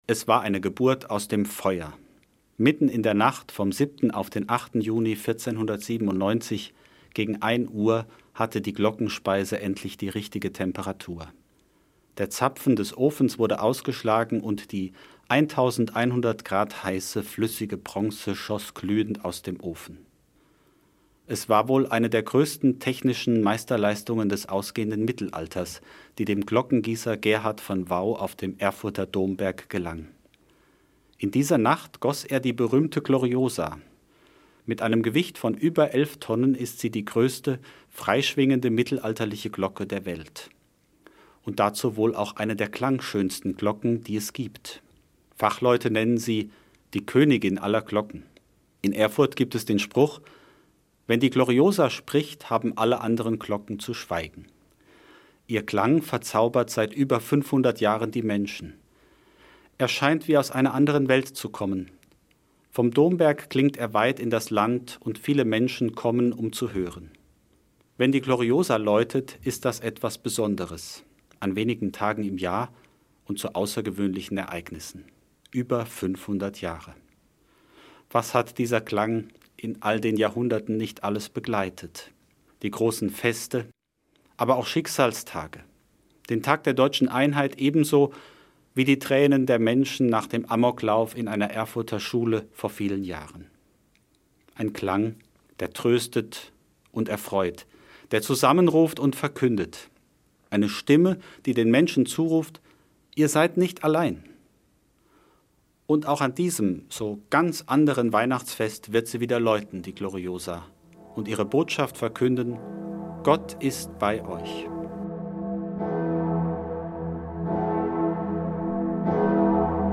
Eine Sendung